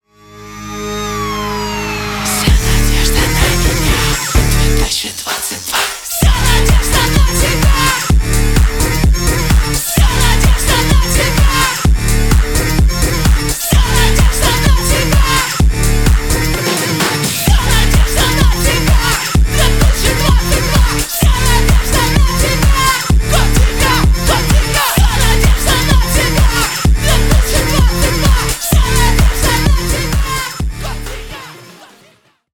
Рок Металл # Танцевальные
громкие